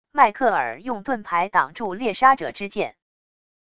迈克尔用盾牌挡住猎杀者之剑wav音频